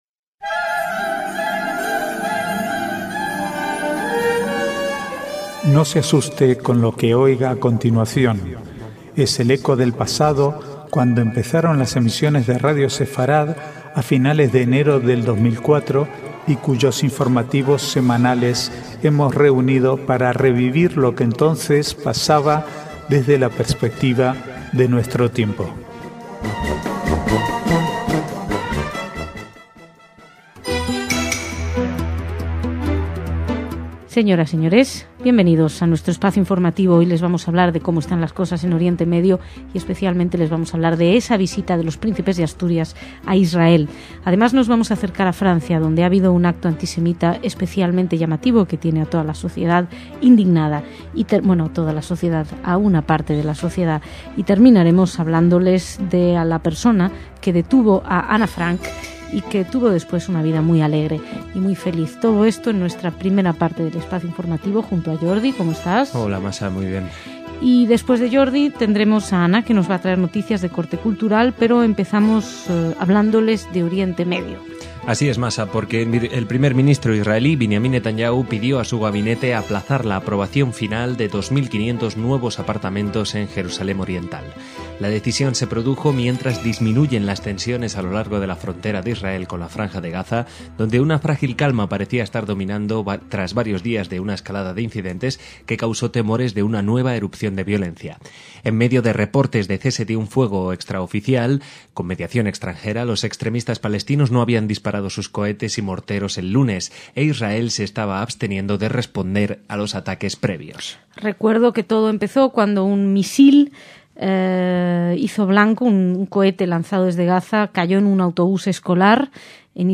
Archivo de noticias del 12 al 15/4/2011